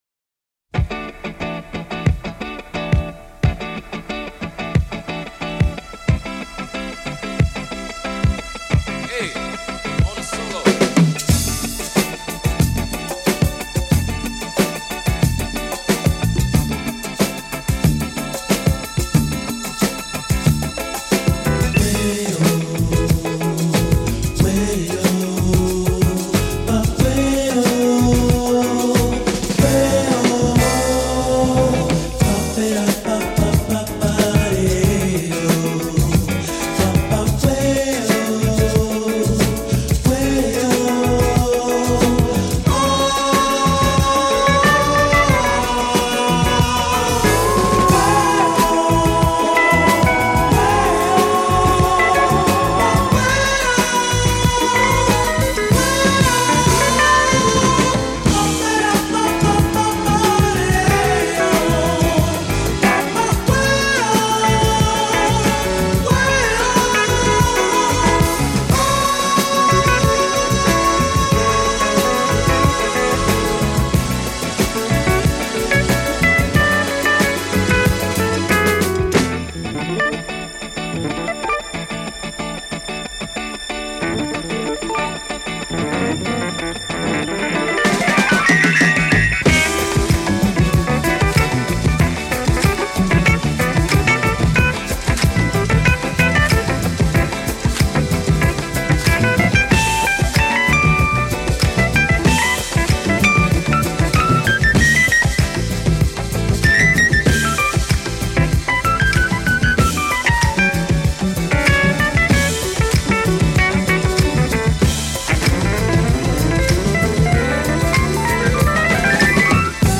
jazz fusion sound and use of electronic keyboards